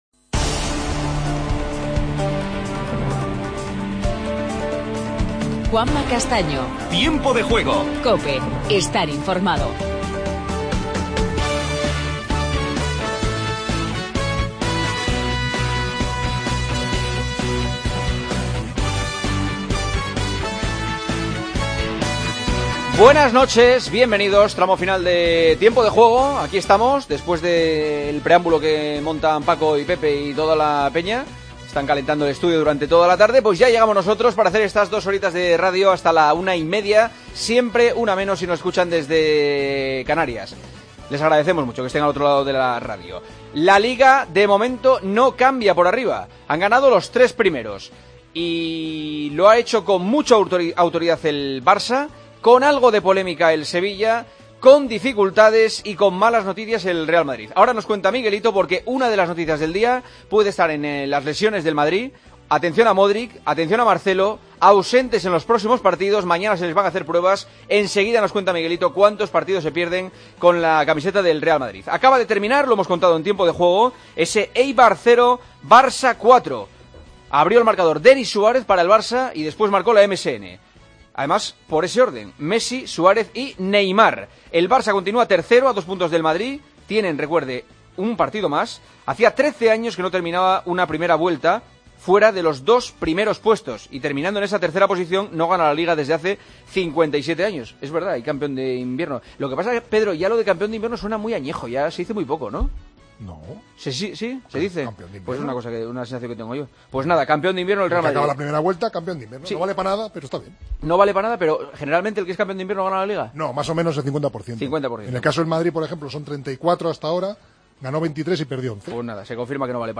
El Barcelona derrota 0-4 al Eibar en el último partido de la 19ª jornada de la Liga. Entrevista a Pedro León y escuchamos a Denis Suárez y Rakitic. El Sevilla continúa segundo tras ganar 3-4 a Osasuna.